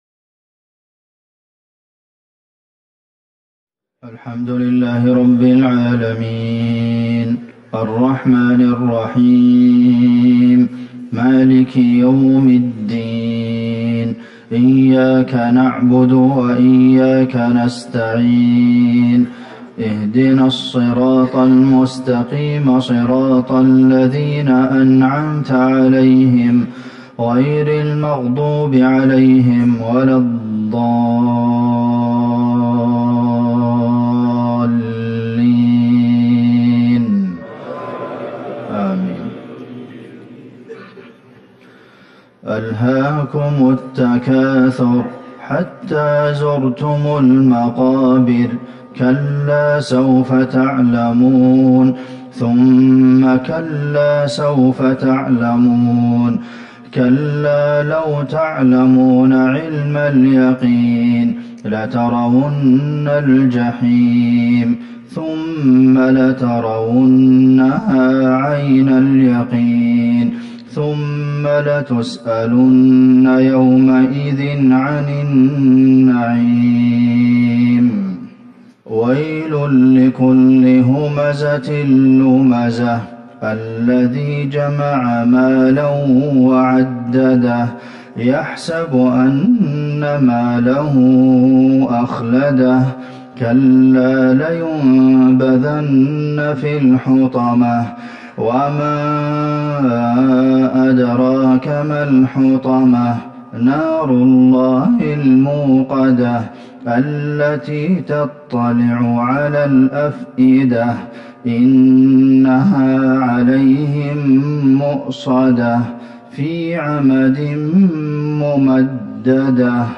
صلاة المغرب ٢١ جمادي الاولى ١٤٤١هـ سورة التكاثر والهمزة Maghrib prayer 9-1-2020 from Surah Al-Takathur and Al-Hamza > 1441 🕌 > الفروض - تلاوات الحرمين